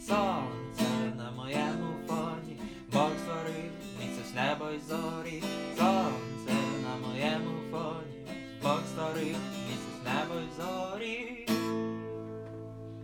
60 просмотров 83 прослушивания 2 скачивания BPM: 150